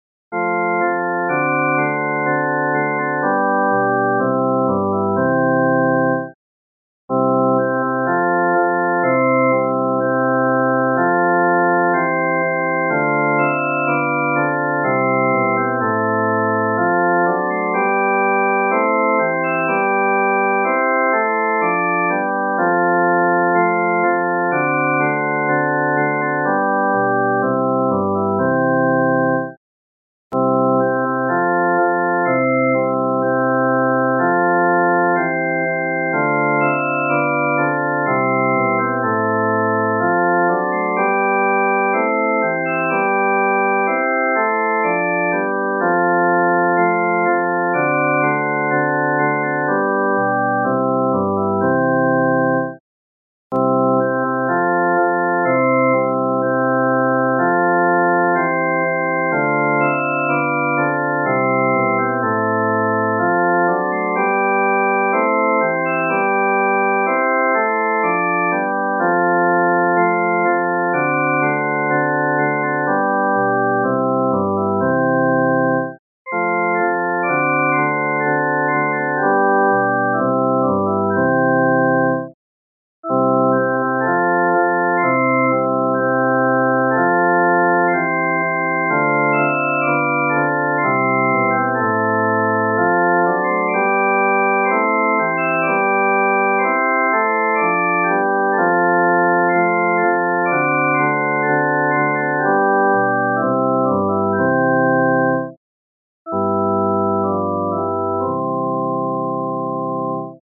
伴奏
四声
本首圣诗由网上圣诗班 (南京）录制